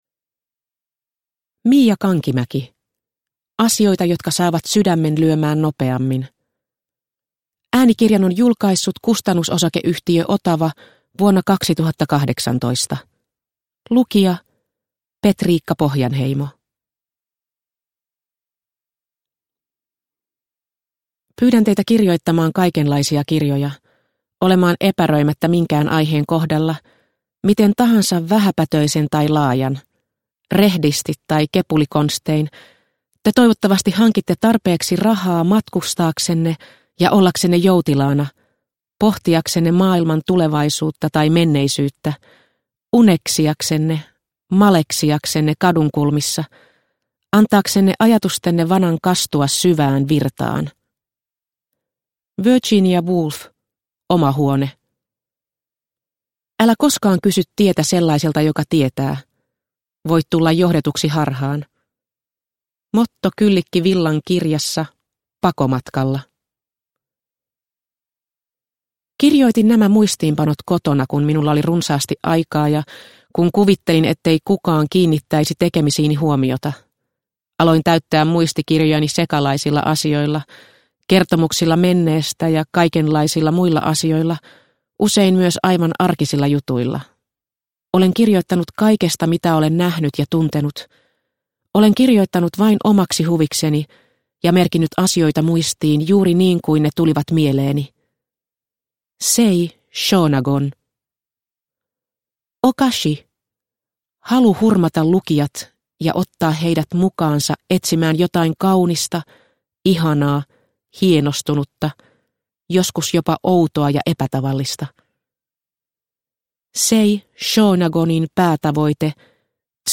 Asioita jotka saavat sydämen lyömään nopeammin – Ljudbok – Laddas ner